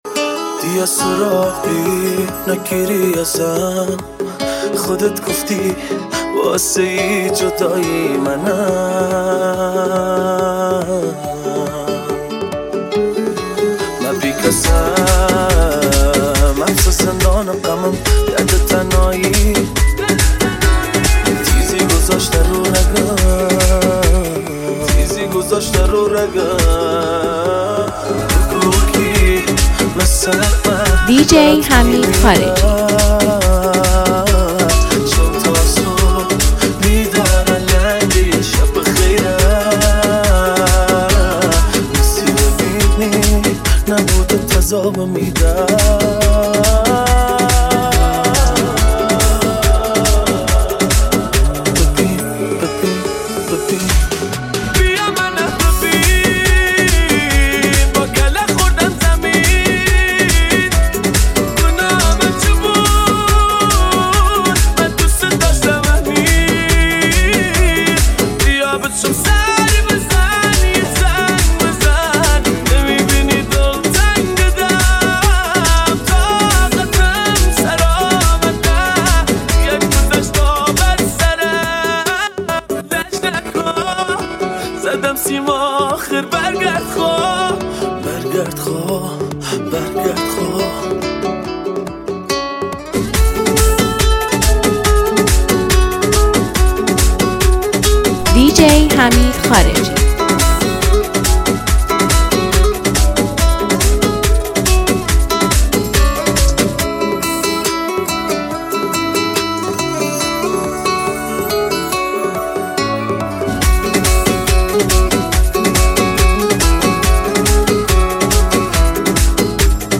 یه ریمیکس کوردی جدید و بی‌نظیر